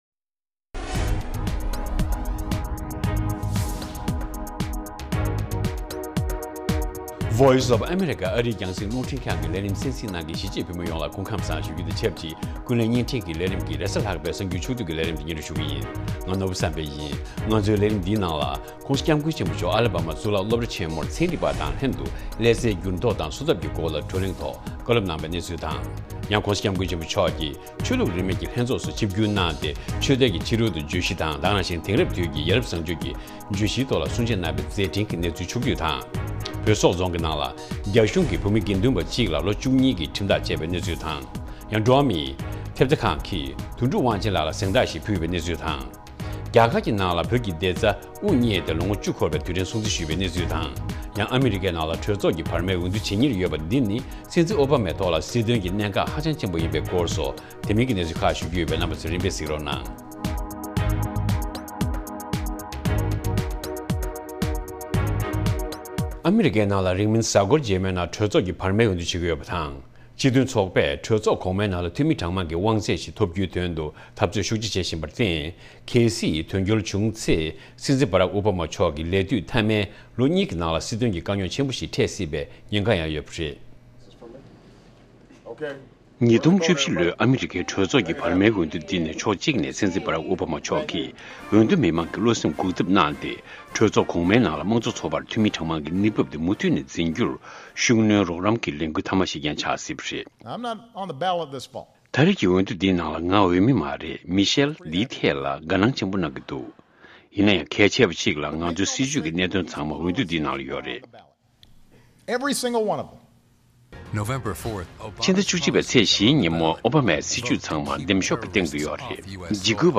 དགོང་དྲོའི་གསར་འགྱུར།